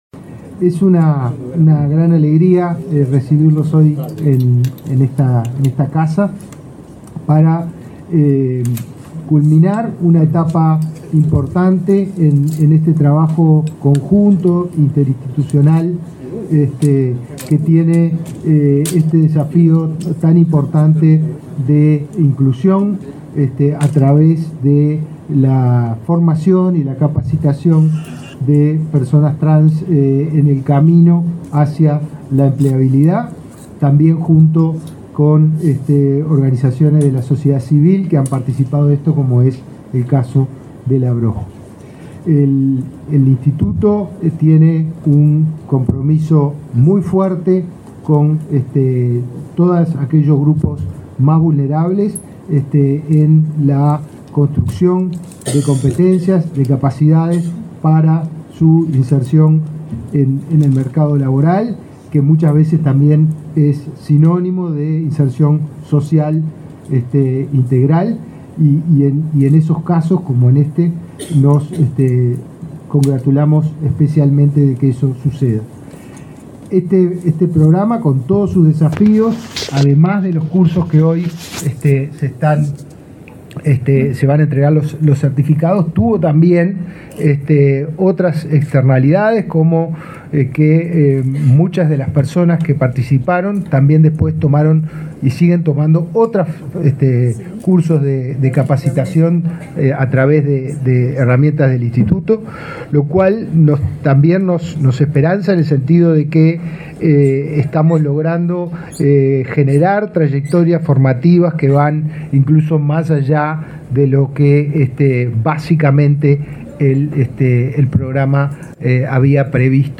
Palabra de autoridades en acto de Inefop
Palabra de autoridades en acto de Inefop 24/11/2023 Compartir Facebook X Copiar enlace WhatsApp LinkedIn El director del Instituto Nacional de Empleo y Formación Profesional (Inefop), Pablo Darscht, y el ministro de Desarrollo Social, Martín Lema, participaron del acto de entrega de diplomas sobre habilidades transversales para la empleabilidad de personas trans, que se realizó este viernes 24 en Montevideo.